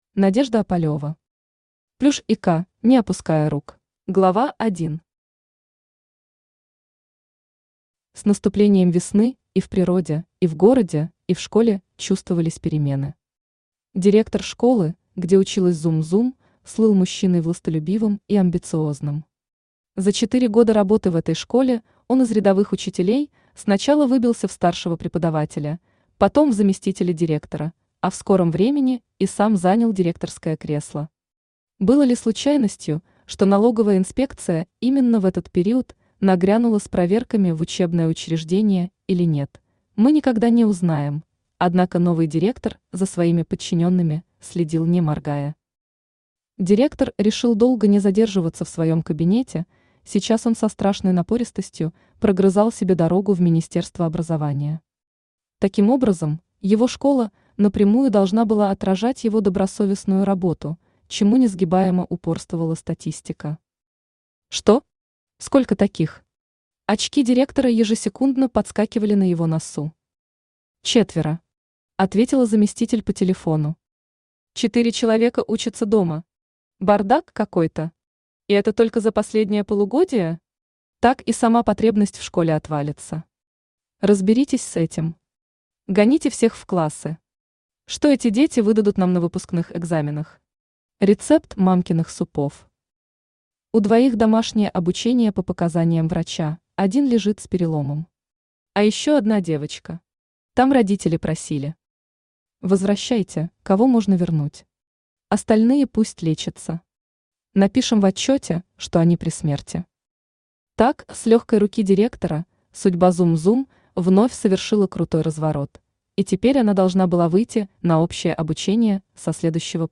Aудиокнига Плюш и Ко: не опуская рук Автор Надежда Опалева Читает аудиокнигу Авточтец ЛитРес.